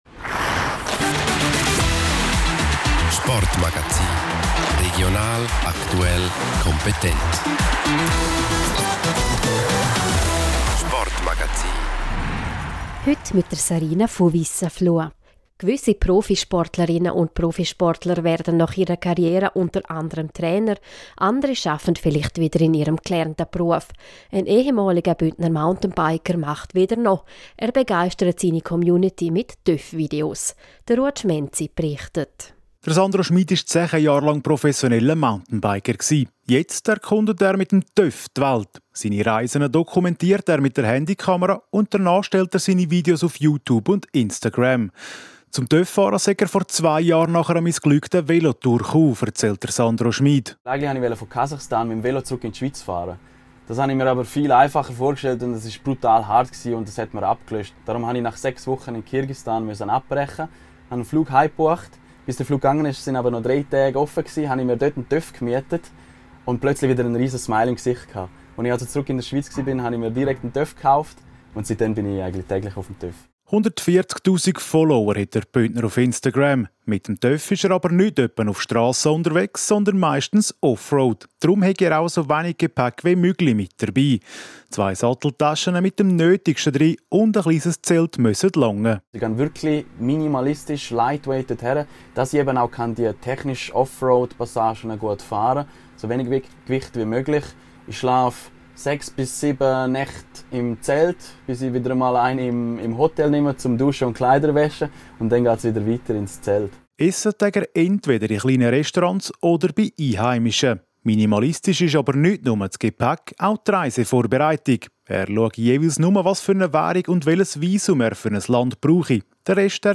• Meldungen zu Sportlerinnen und Sportlern sowie Teams aus dem Sendegebiet.